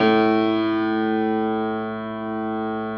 53g-pno05-A0.wav